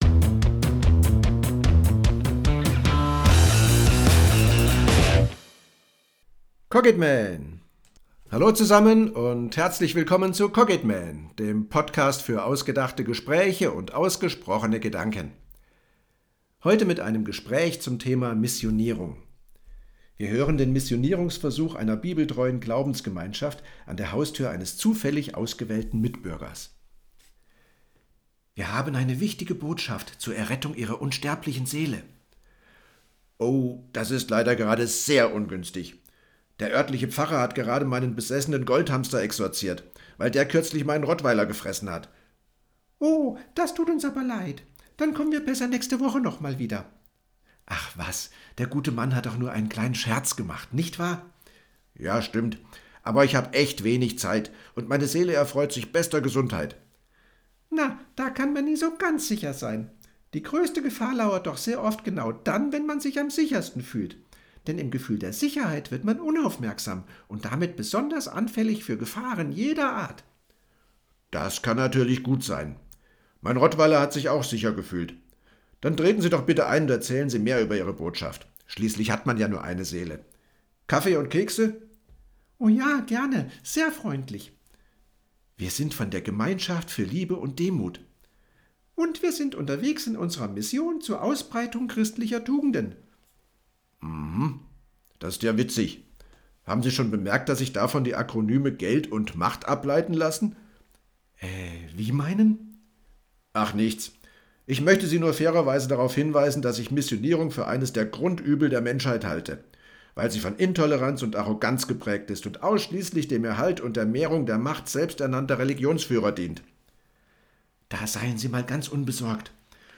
Dialog-Missionierung.mp3